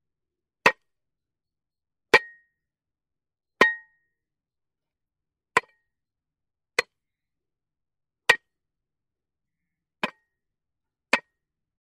Звуки кирки
На этой странице собрана коллекция звуков ударов кирки по разным поверхностям.
Работают киркой